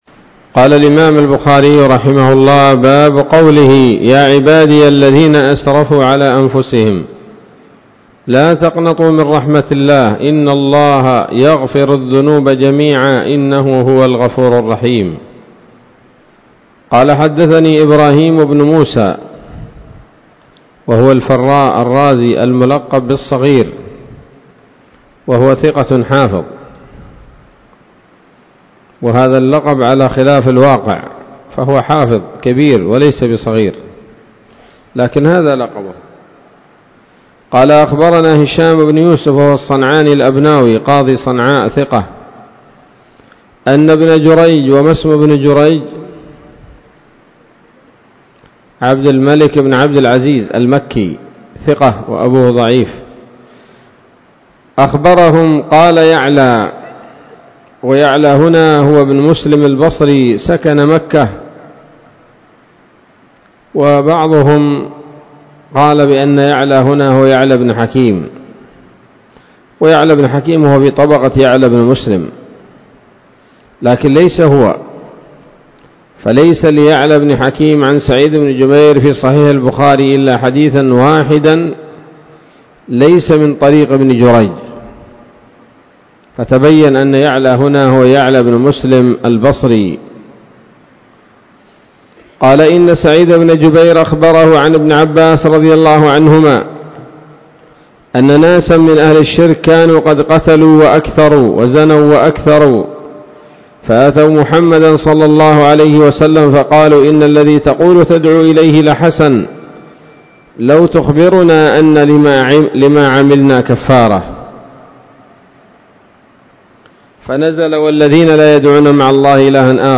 الدرس السابع عشر بعد المائتين من كتاب التفسير من صحيح الإمام البخاري